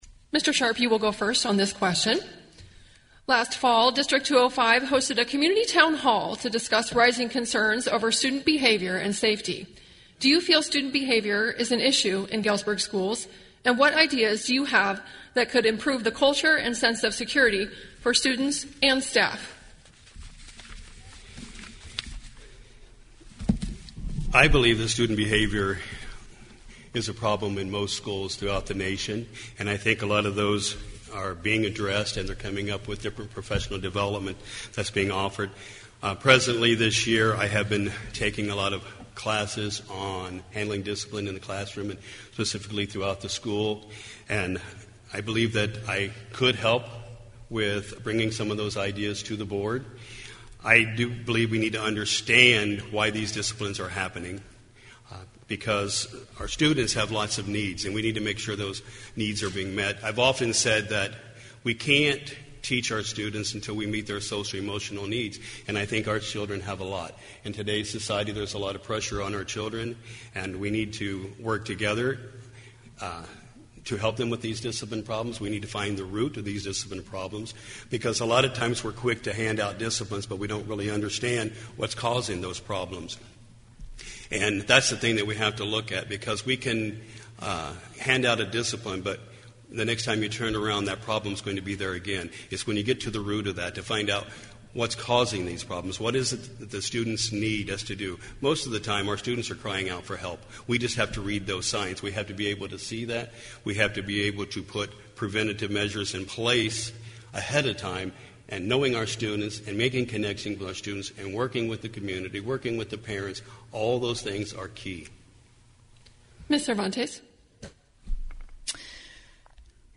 Candidates for Galesburg City Council and District 205 School Board participated in a Galesburg Candidate Forum on March 14 in Hegg Performing Arts Center at Galesburg High School.